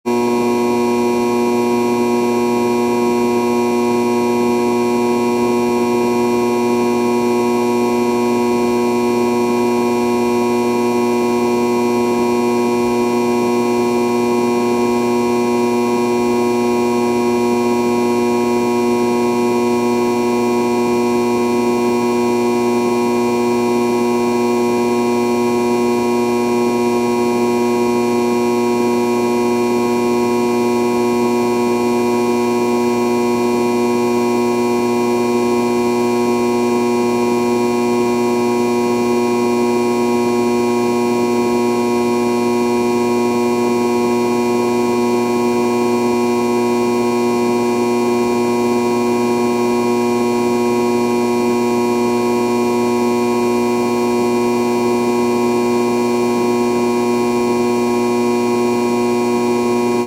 Звук трансформаторной будки